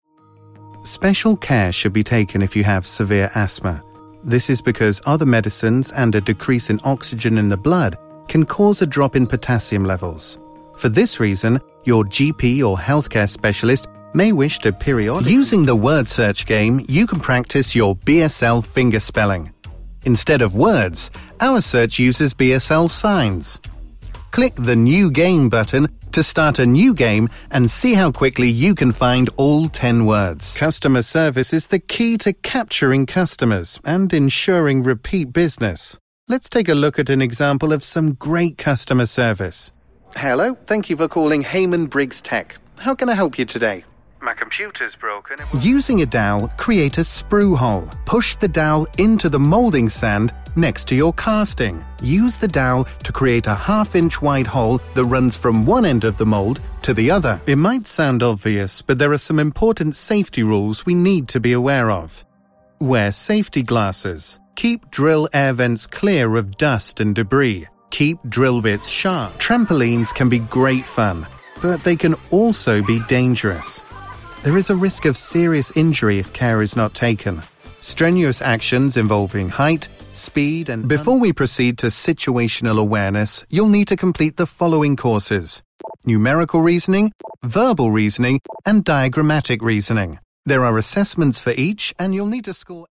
Hallo, ich bin ein britischer männlicher Sprecher mit einer warmen und vertrauenswürdigen Stimme, die sich für Unternehmenspräsentationen und E-Learning eignet, aber auch die Bandbreite hat, um an skurrilen Werbespots, Animationen und Videospielen zu arbeiten.
Sprechprobe: eLearning (Muttersprache):
Hi, I'm a male British voiceover with a warm and trustworthy voice suitable for corporate narration and elearning but with the range to also work on quirky commercials, animation, and video games.